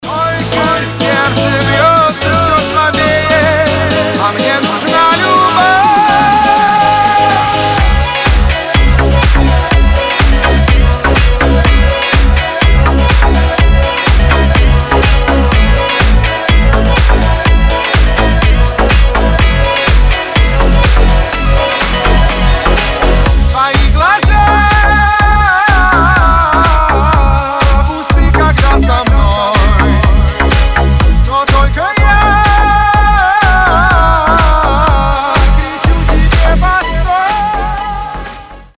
• Качество: 320, Stereo
мужской вокал
громкие
remix
восточные мотивы
dance
house